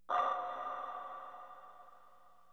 EFFSCARY.WAV